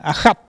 Cri pour chasser le chat ( prononcer le crti )